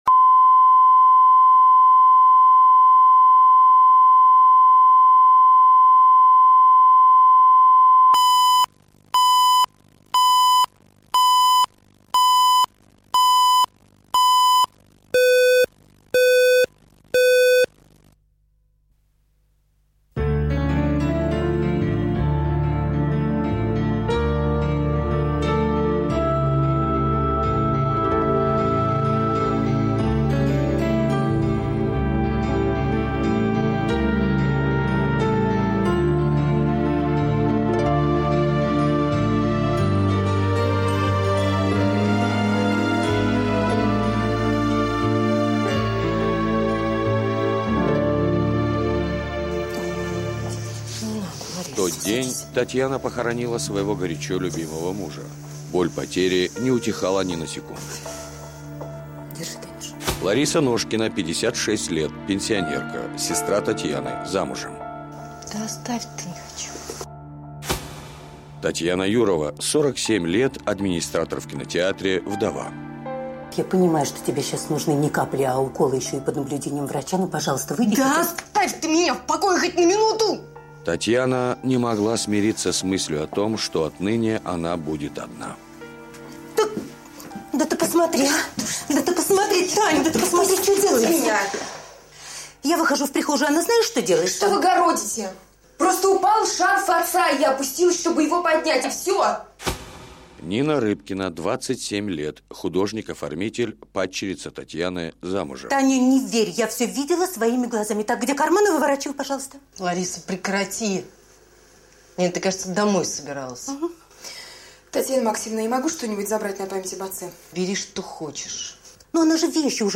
Аудиокнига Письма с того света | Библиотека аудиокниг